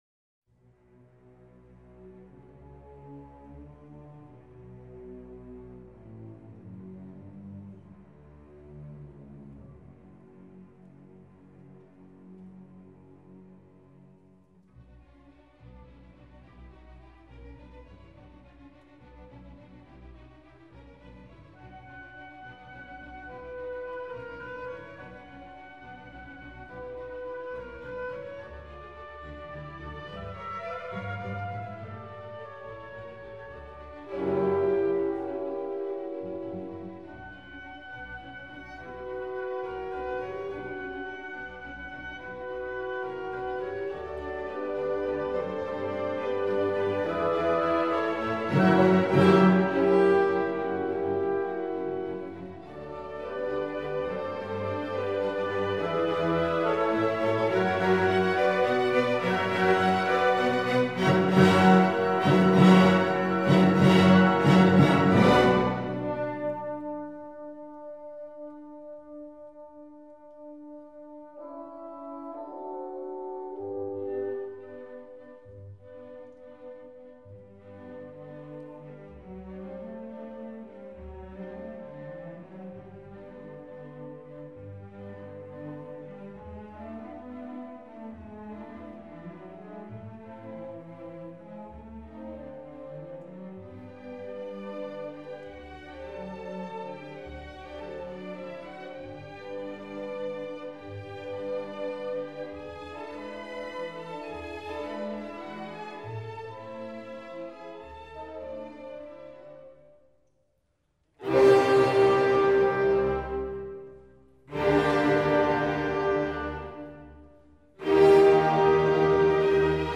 Tem� pl�tojimo epizode vyrauja ��angos tema. Kulminacijoje ��angos melodija skamba itin valingai, pabr��tai. Tik baigiamajame tem� pl�tojimo etape konfliktas rimsta, tylantis orkestras ruo�ia repriz� ir pagrindin�s temos pasirodym�.